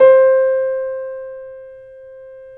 Index of /90_sSampleCDs/Roland L-CD701/KEY_YC7 Piano pp/KEY_pp YC7 Mono